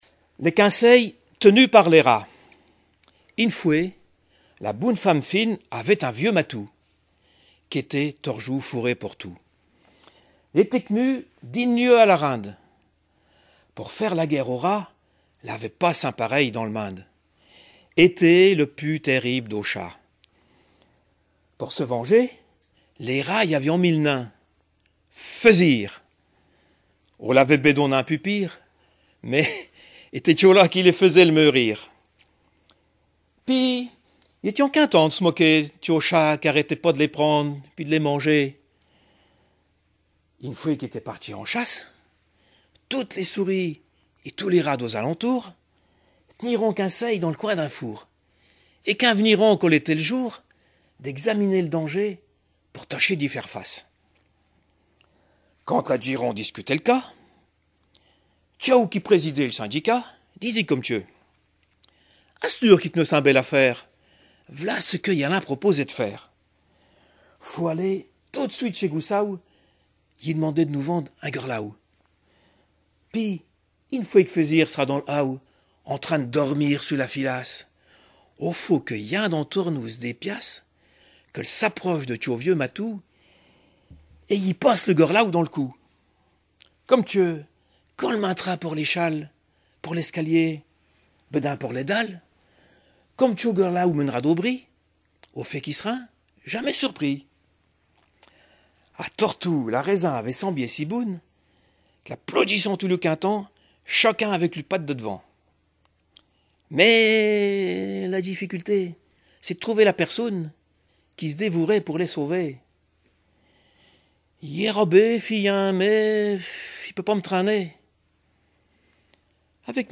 Informateur(s) Les Joyeux vendéens association
Genre fable
Catégorie Récit